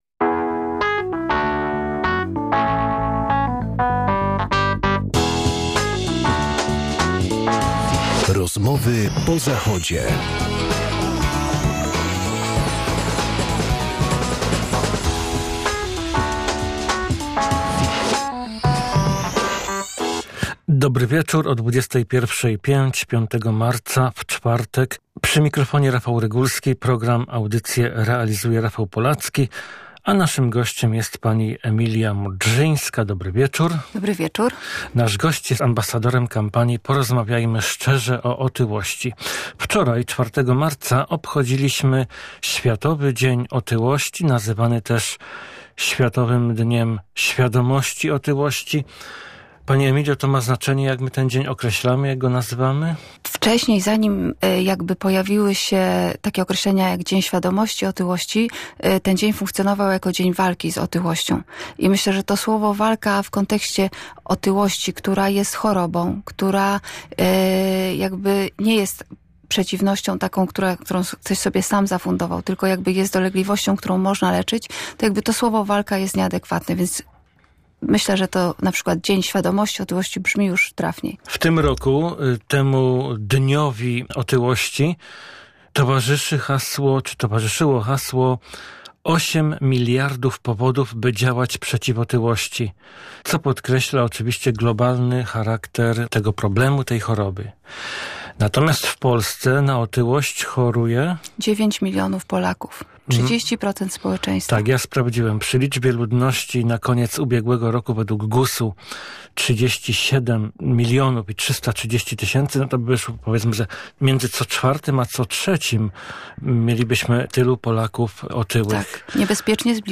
Zapraszamy do wysłuchania rozmowy, której bohaterka szczerze też opowiada o własnym doświadczeniu tej choroby.